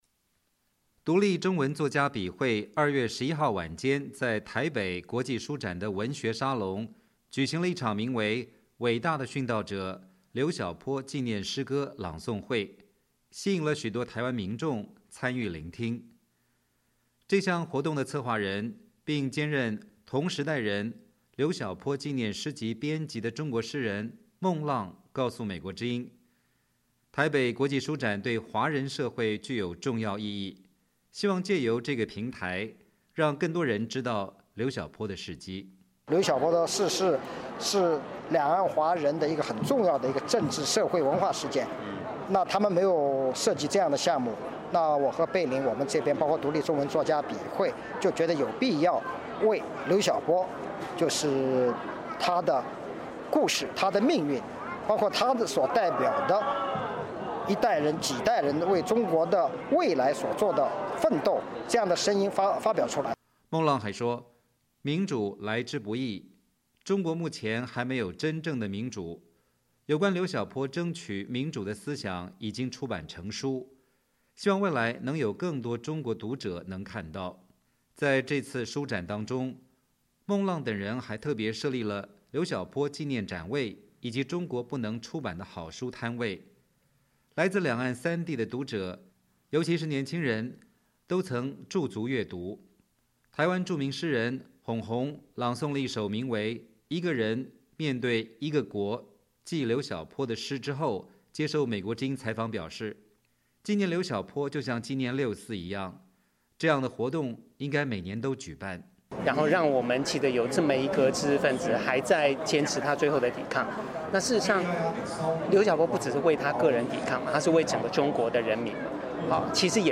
两岸的作家及诗人在台北国际书展举办期间，共同参与了一项纪念刘晓波的诗歌朗诵会。